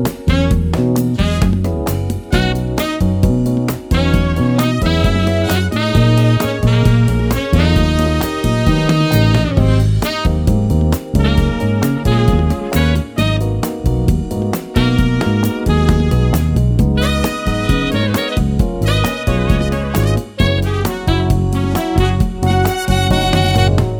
Three Semitones Down End Cut Soul / Motown 3:53 Buy £1.50